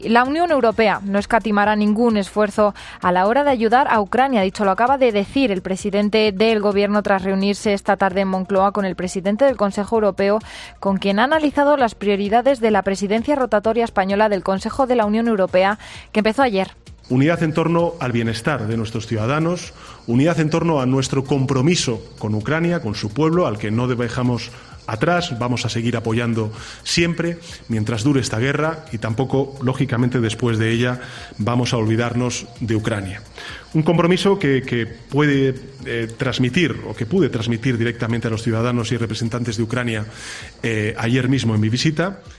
Así se ha expresado Sánchez este domingo durante su comparecencia junto al presidente del Consejo Europeo, Charles Michel, tras la reunión de trabajo que ambos han mantenido en el Palacio de la Moncloa.
Durante la declaración institucional sin lugar a preguntas por parte de los medios, Sánchez ha repasado las cuatro prioridades que marcarán la agenda de la presidencia española europea como son la reindustrialización del continente y diversificación de las relaciones comerciales con el exterior y la transición ecológica y economía circular.